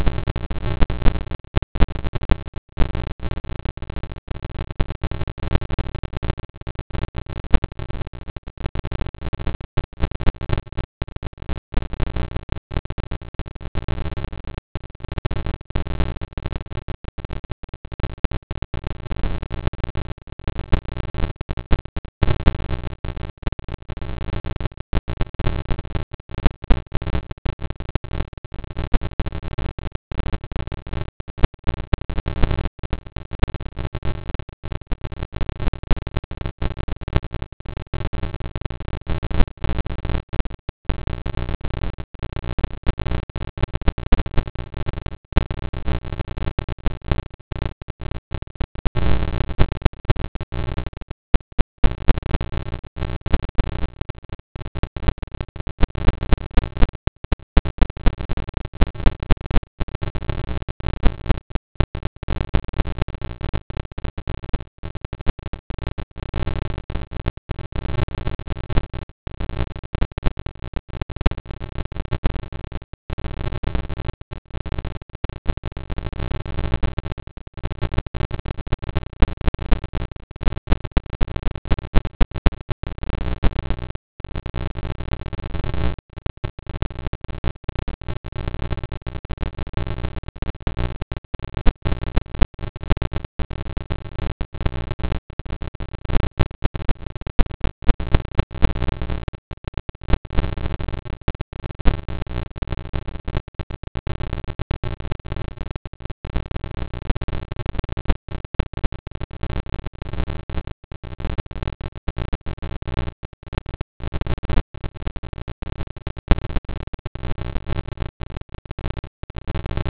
pulsar sound